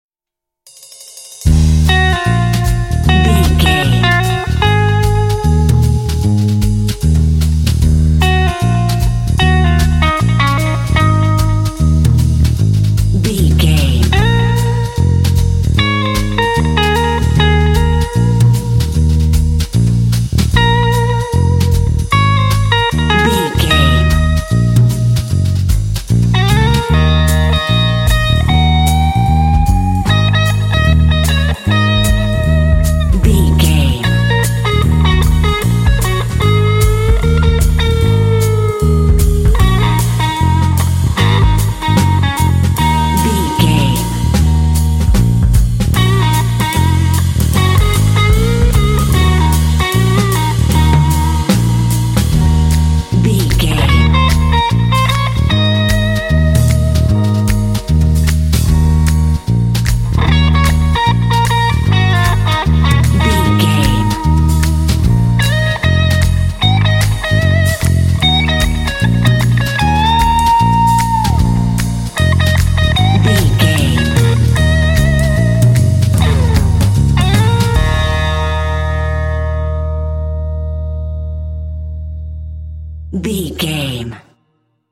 Aeolian/Minor
E♭
dreamy
optimistic
uplifting
bass guitar
drums
electric guitar
piano
jazz
swing